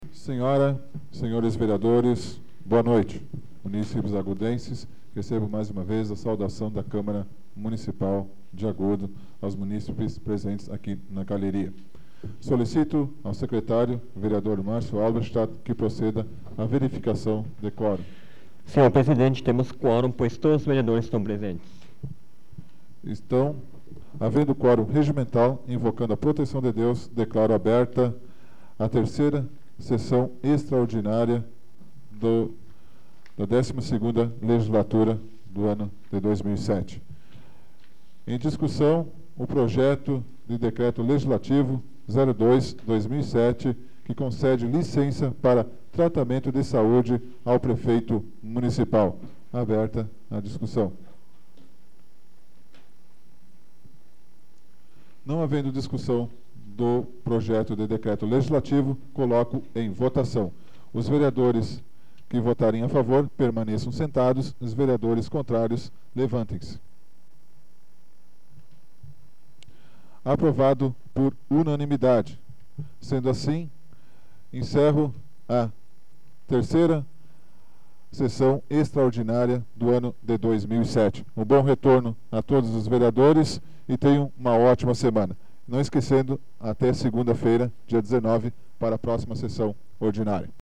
Áudio da 33ª Sessão Plenária Extraordinária da 12ª Legislatura, de 12 de março de 2007